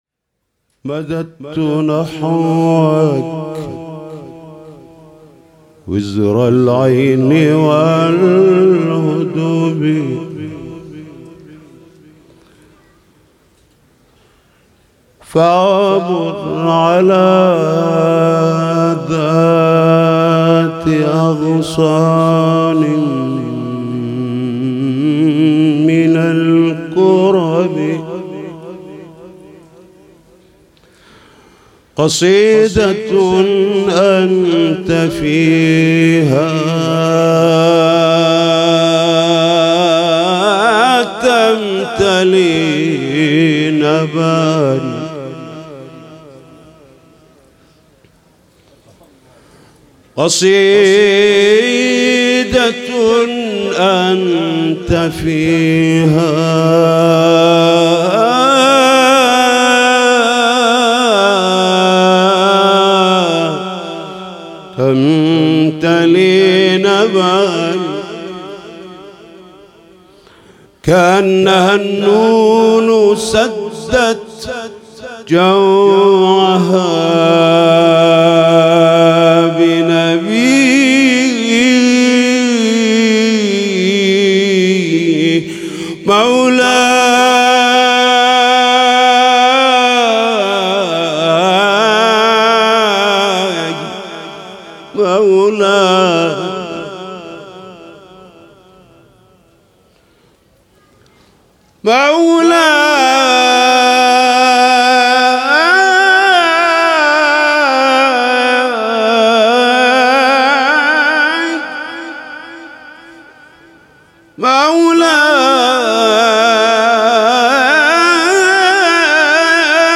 عید غدیر97 - ابتهال مددت نحوک و زالعین و الهب